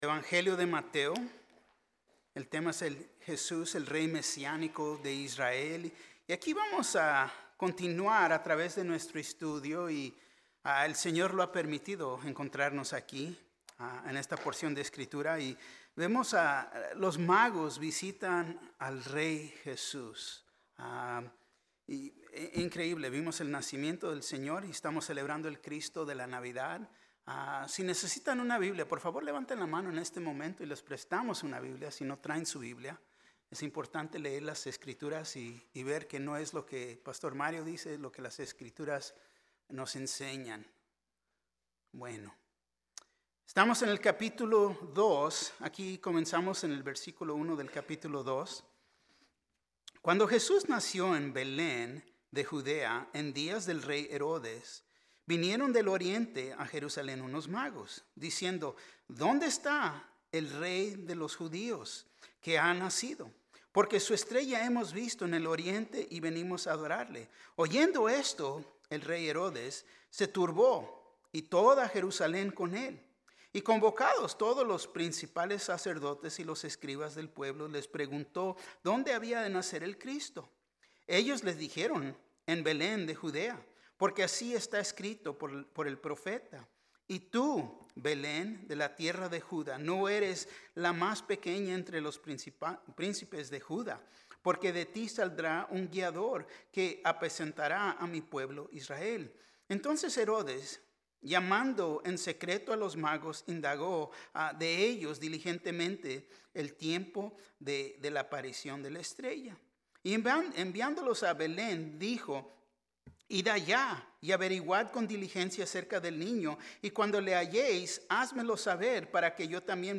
Mensaje